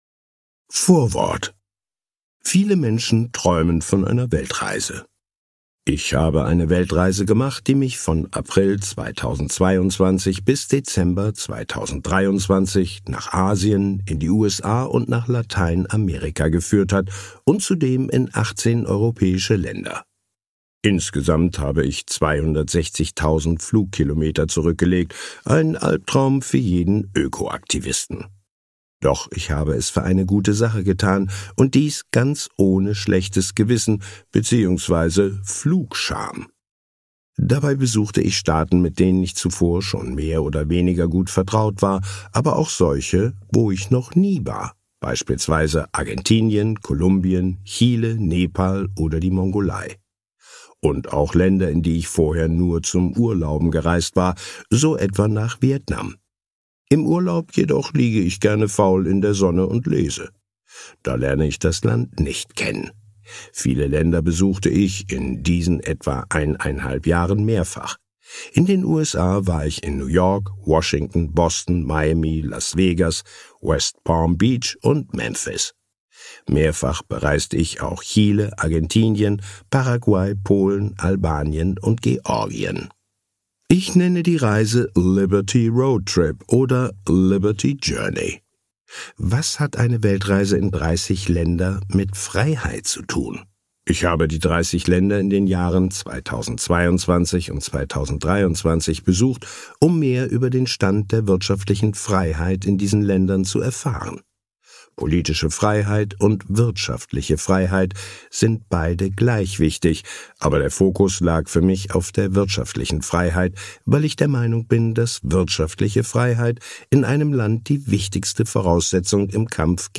Episode #210 - Hörbuch: Weltreise eines Kapitalisten - Vorwort ~ Dr. Dr. Rainer Zitelmann: Erfolg, Reichtumsforschung und Finanzen Podcast